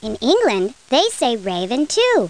00773_Sound_raven.England.mp3